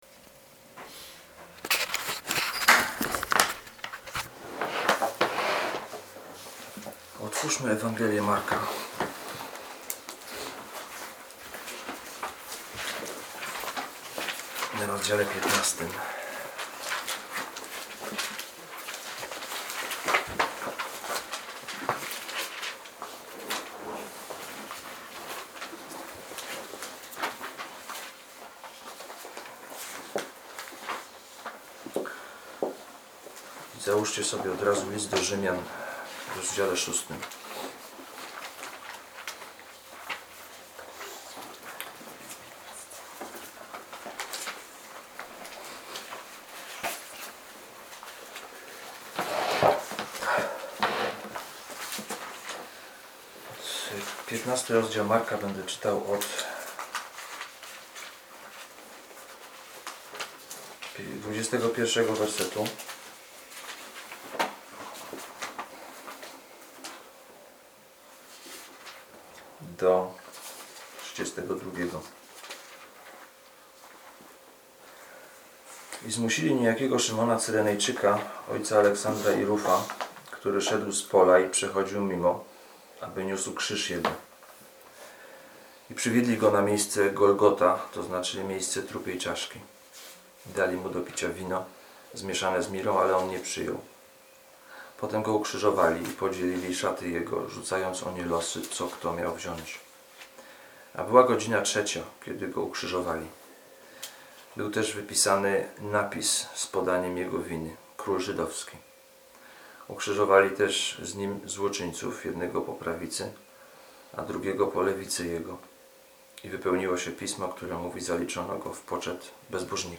Ulica Prosta - Kazania z 2013